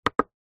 Беспроводное радио Bluetooth, динамик, нажатие кнопки 2.
besprovodnoe-radio-bluetooth-dinamik-nazhatie-knopki-2.mp3